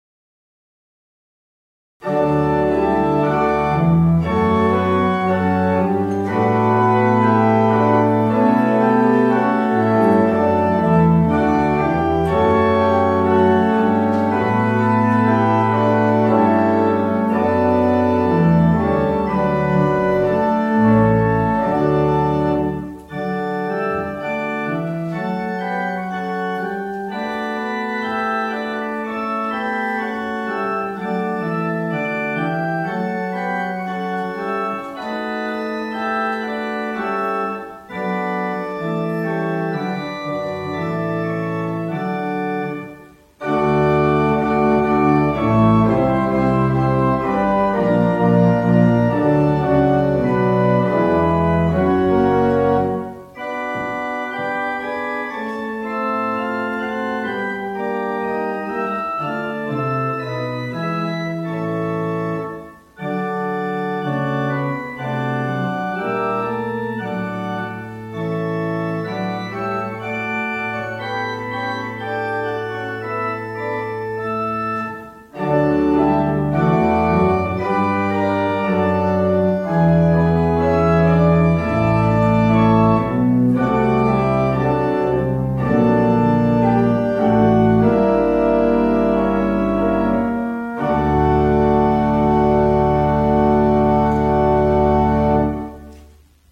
Gottesdienst vom Sonntag Judika nachhören
Orgelmusik